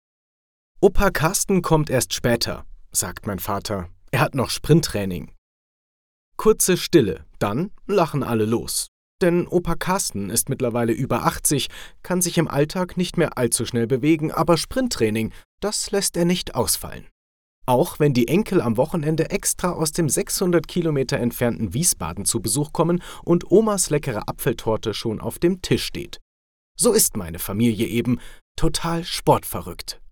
Professioneller Sprecher & Moderator
Mein hauseigenes Studio in Broadcast-Qualität sorgt für exzellente Ergebnisse bei vielfältigen Projekten.
2 | Hörbuch